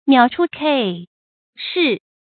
邈处欿视 miǎo chǔ jī shì
邈处欿视发音